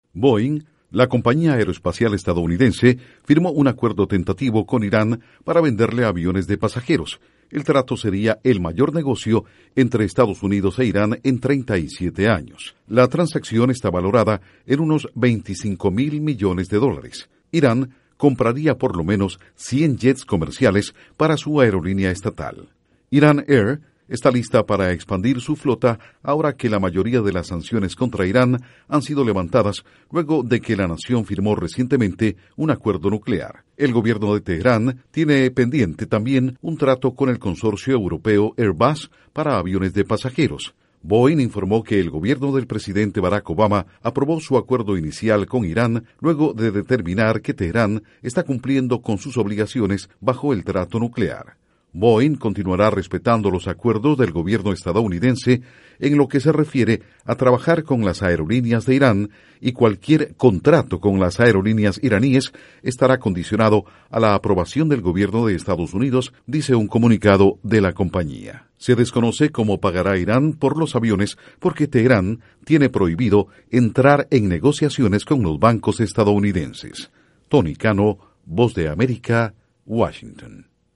Estados Unidos está dispuesto a vender 100 aviones Boeing comerciales a Irán. Informa desde la Voz de América en Washington